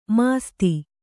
♪ māsti